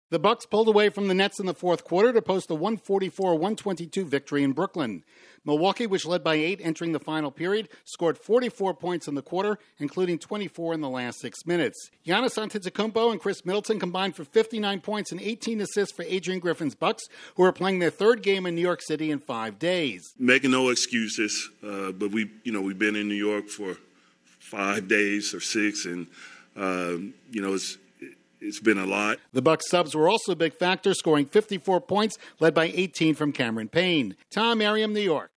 The Bucks won for the eighth time in nine games. Correspondent